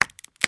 Звуки пластиковой крышки
Звук сорванной пломбы на пластиковой крышке